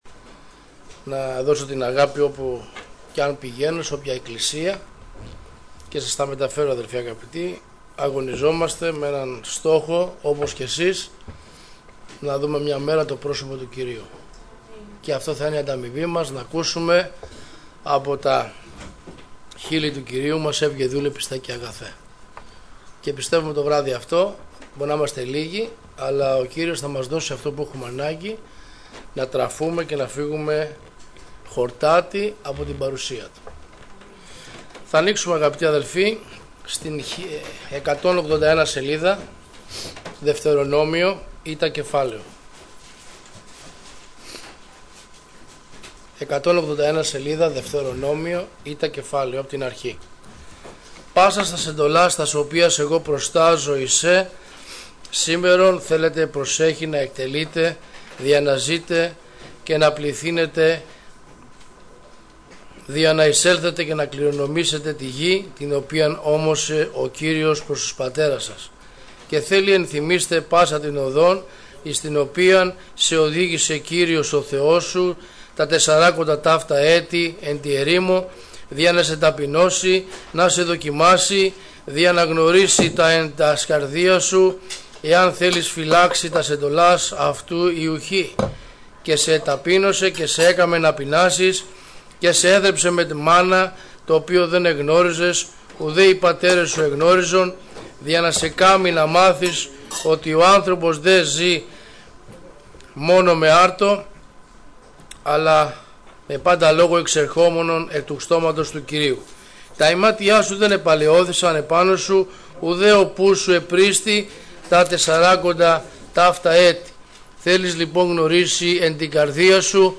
Διάφοροι Ομιλητές Λεπτομέρειες Σειρά: Κηρύγματα Ημερομηνία